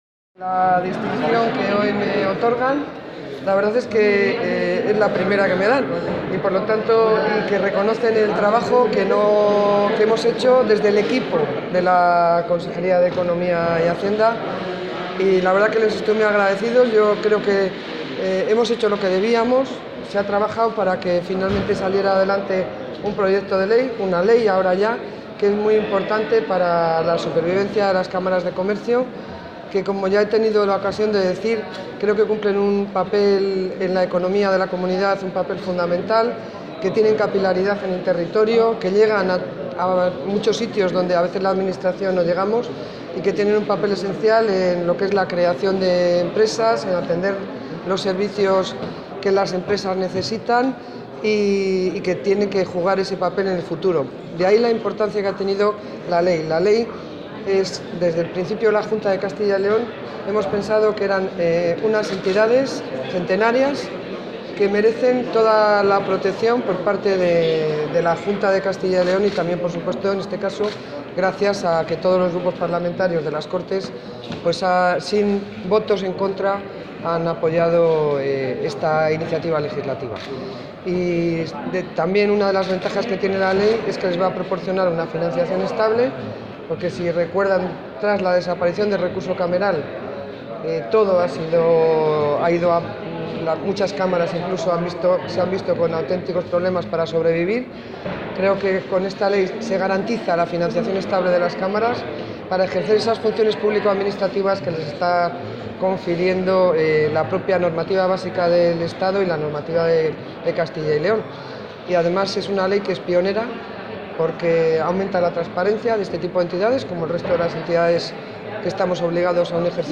Declaraciones de la consejera de Economía y Hacienda.